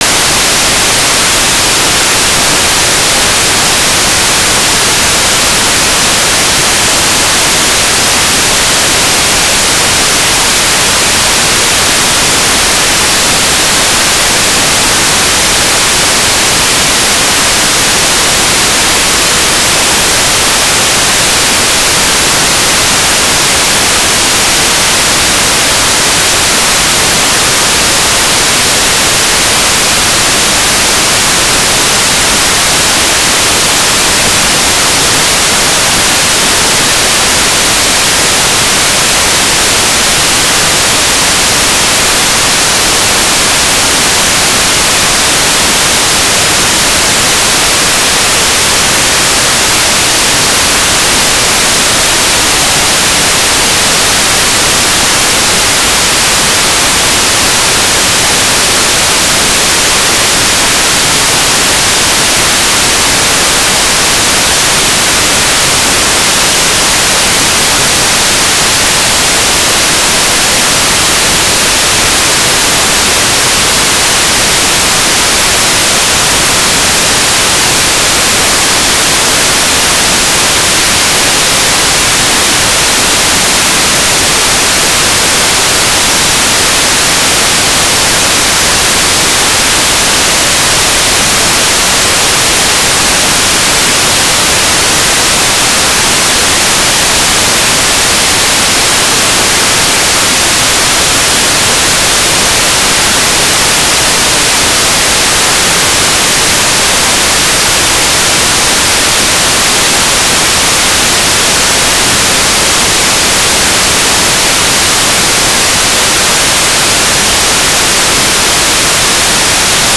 "transmitter_description": "Mode U - AFSK 1143 Beacon TLM",
"transmitter_mode": "AFSK",